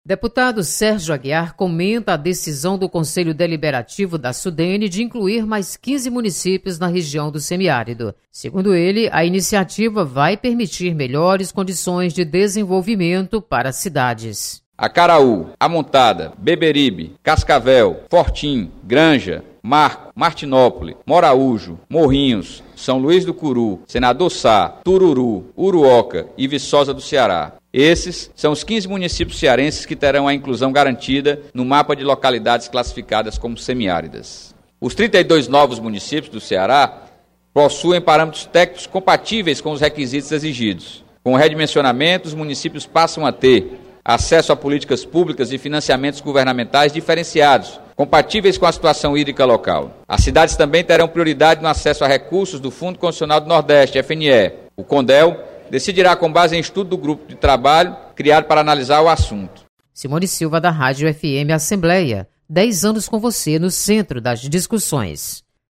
Deputado Sérgio Aguiar comemora ação da Sudene. Repórter